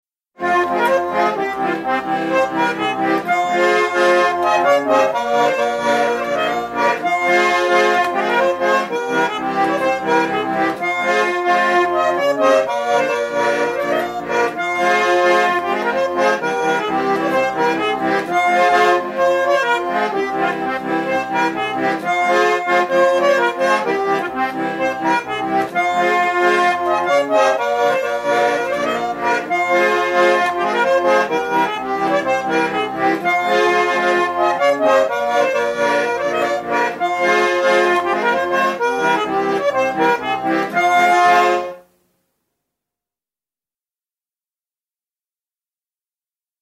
Pillilugu “Tuustep”
Setomaa